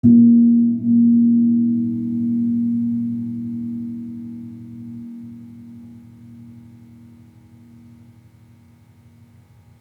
Gamelan Sound Bank
Gong-A#2-p.wav